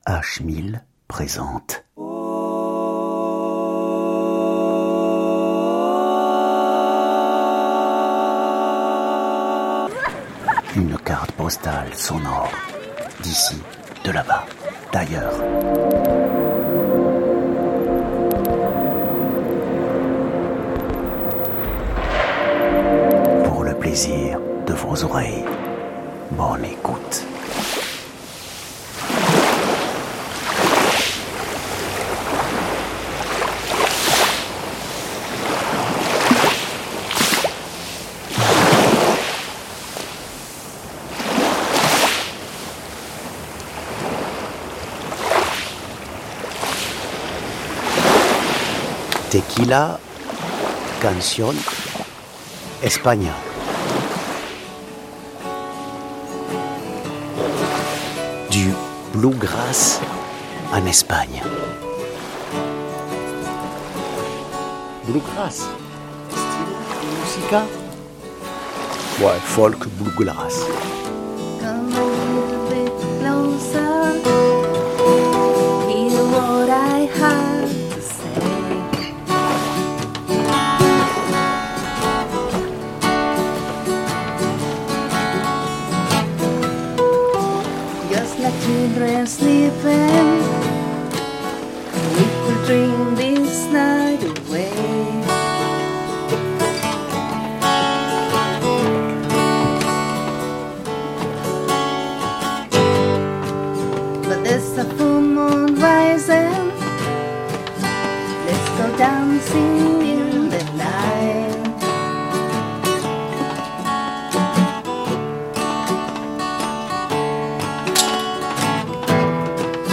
Guitare folk et bord de mer en Espagne
Profitez d'une balade auditive en bord de mer en Espagne avec des guitare folk dans H1000